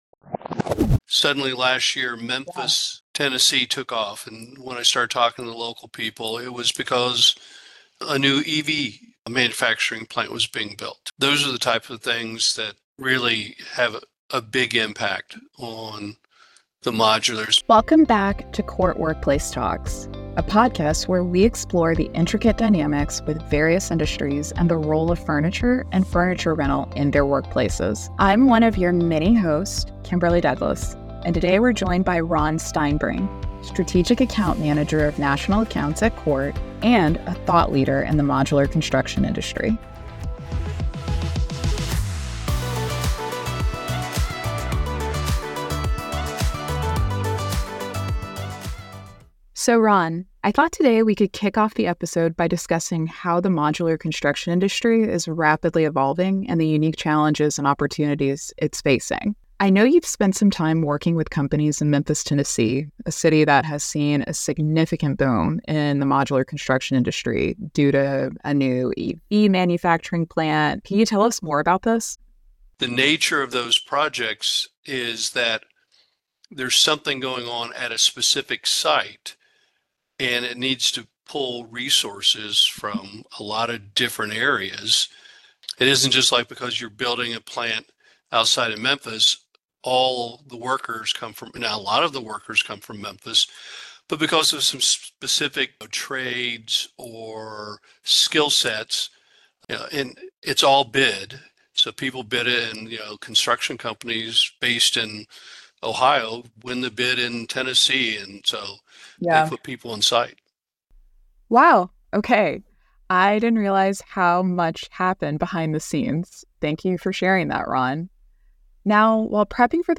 Before we start, we encourage you to listen to our recent podcast episode of CORT Workplace Talks.